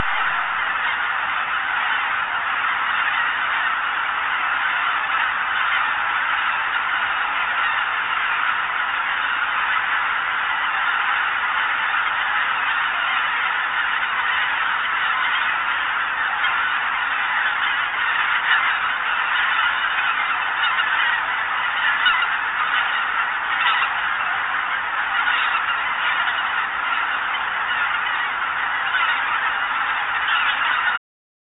ねぐら入りのRealMovie(1691KB)